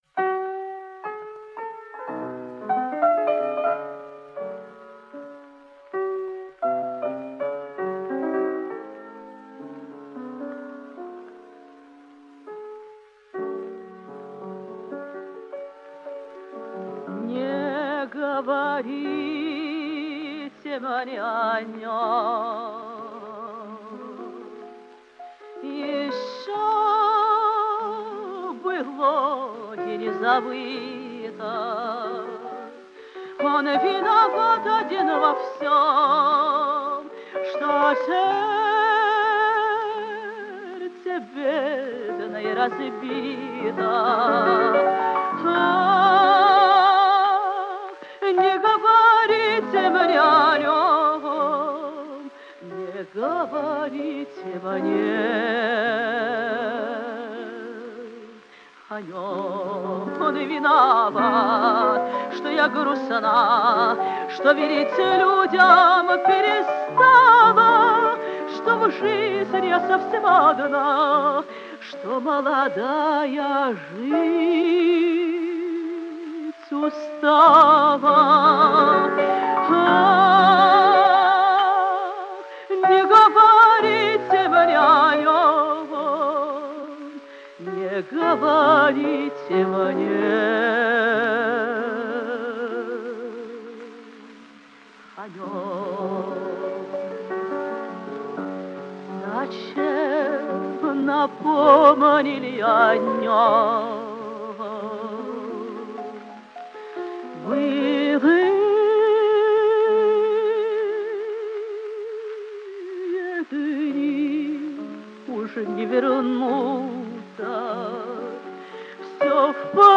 Жанр: Романсы